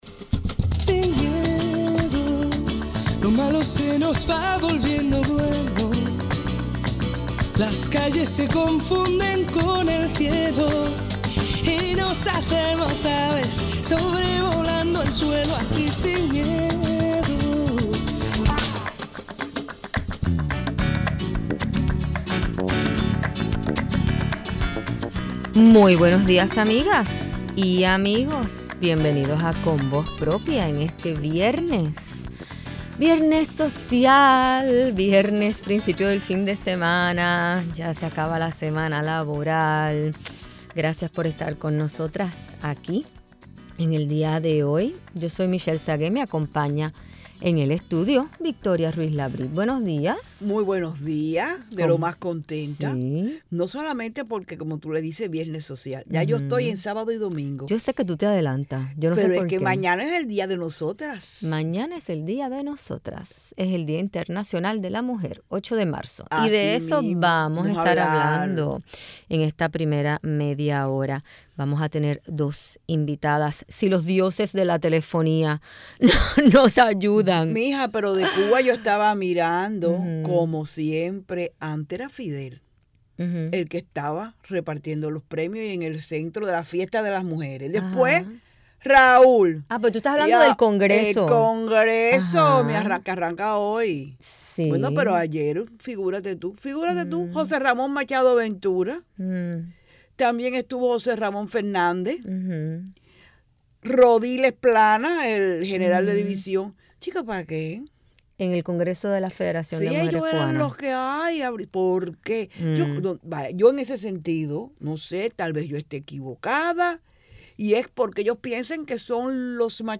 Hablamos con la historiadora